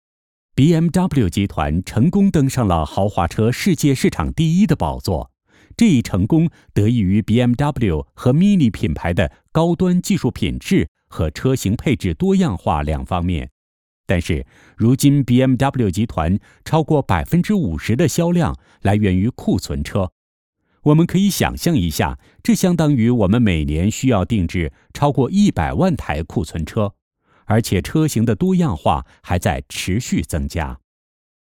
Accent-Free Chinese Voice Talent